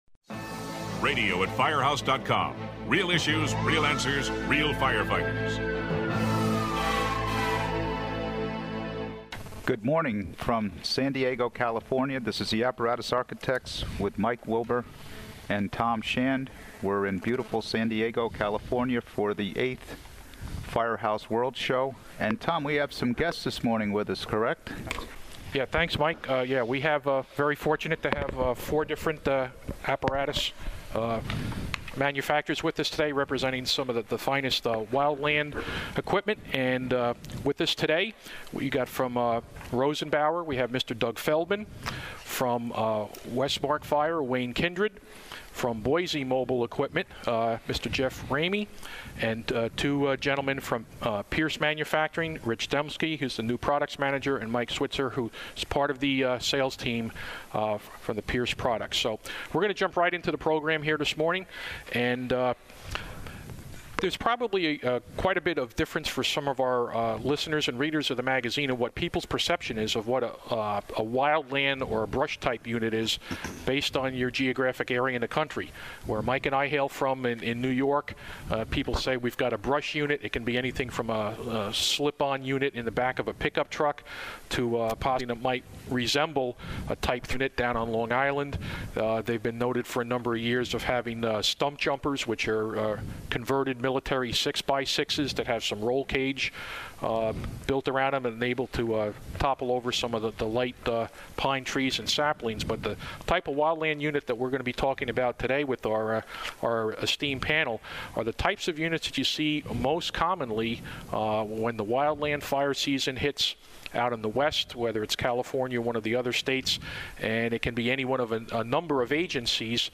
This podcast was recorded at Firehouse World in March.